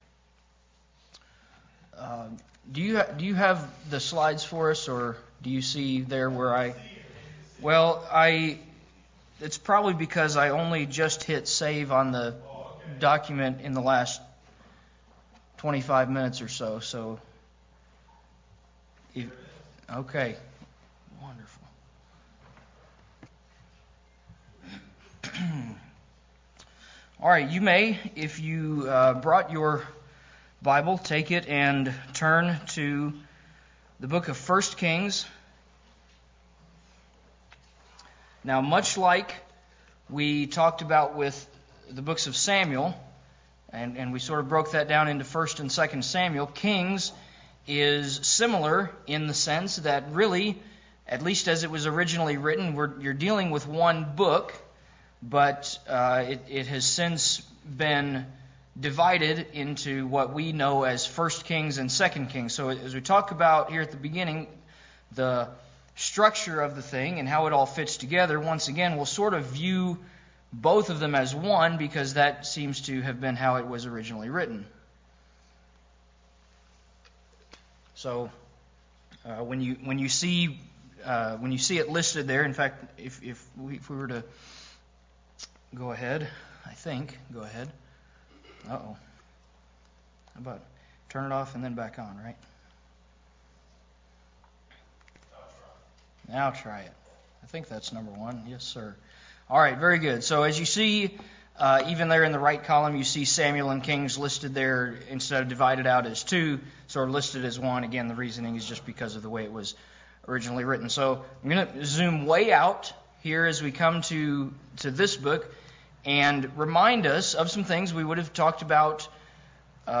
Wednesday Study